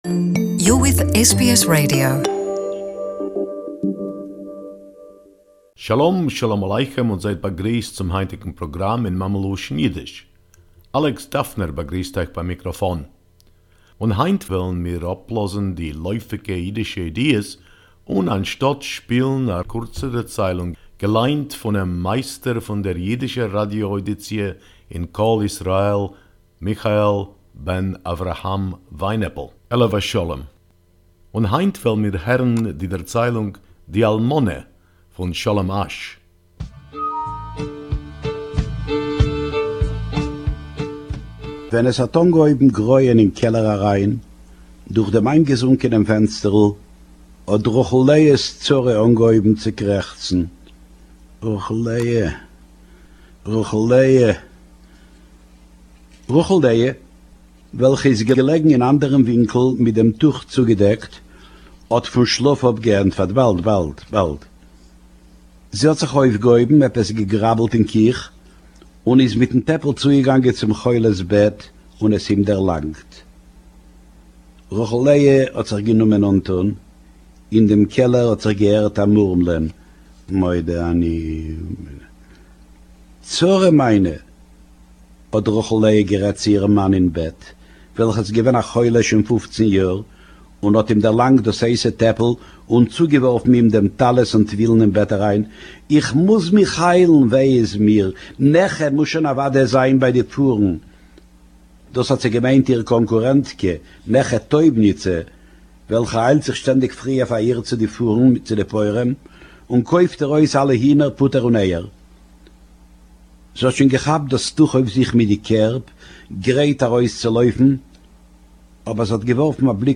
Yiddish Story, "Di Almoneh"